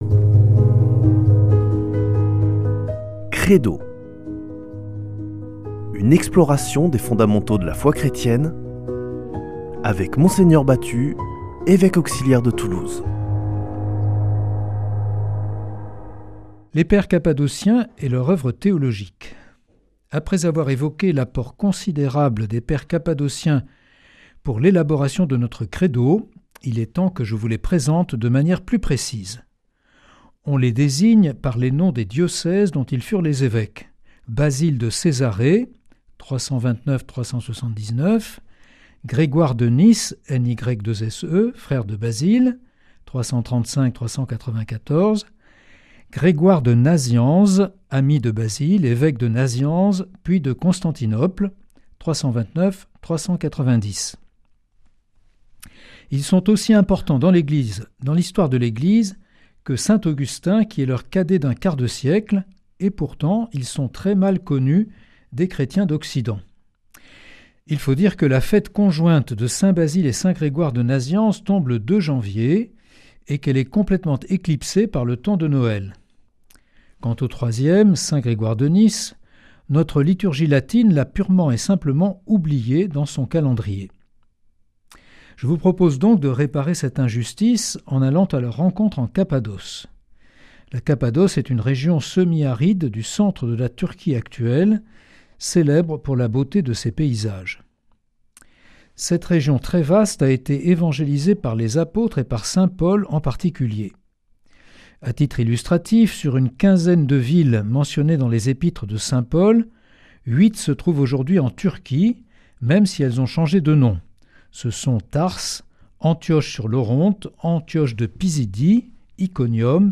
Partager Copier ce code (Ctrl+C) pour l'intégrer dans votre page : Commander sur CD Une émission présentée par Mgr Jean-Pierre Batut Evêque auxiliaire de Toulouse Voir la grille des programmes Nous contacter Réagir à cette émission Cliquez ici Qui êtes-vous ?